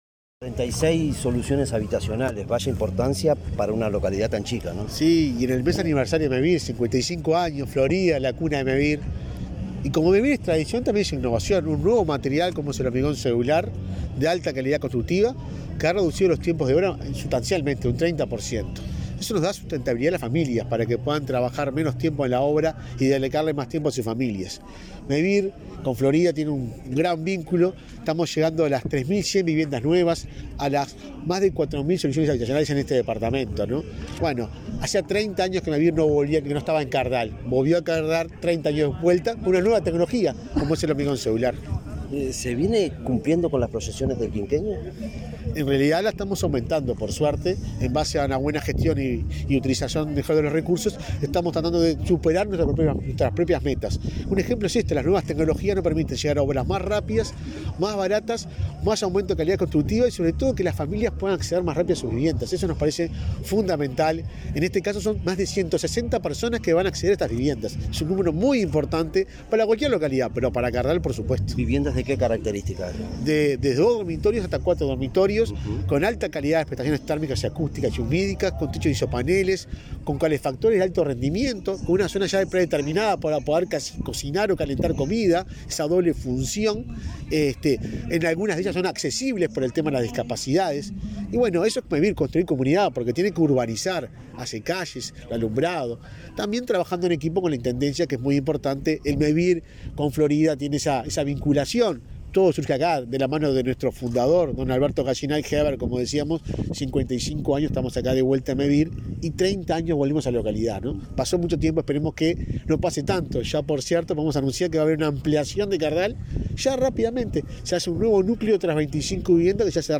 Declaraciones del presidente de Mevir a la prensa
Declaraciones del presidente de Mevir a la prensa 26/05/2022 Compartir Facebook X Copiar enlace WhatsApp LinkedIn Este jueves 26, el presidente de Mevir, Juan Pablo Delgado, inauguró 25 viviendas nucleadas en la localidad de Cardal y zonas aledañas, en el departamento de Florida, y, luego, dialogó con la prensa.